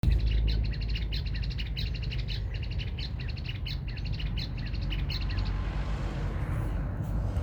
Greater Wagtail-Tyrant (Stigmatura budytoides)
Province / Department: Tucumán
Location or protected area: Ruta 307 entre El Infiernillo y Amaicha del Valle
Condition: Wild
Certainty: Photographed, Recorded vocal